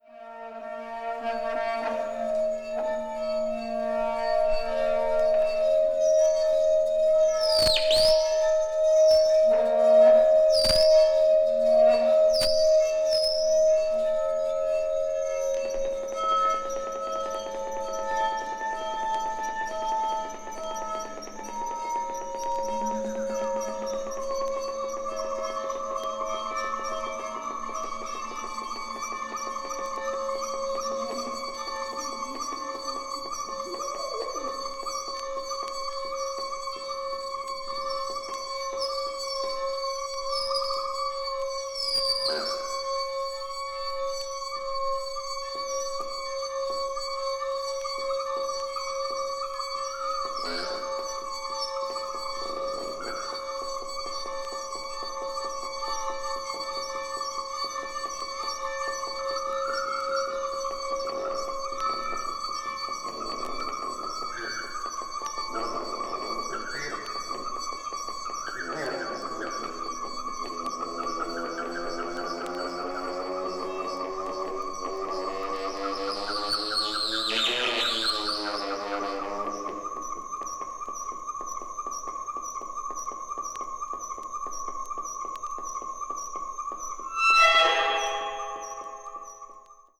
avant-garde   experimental   free improvisation   sound art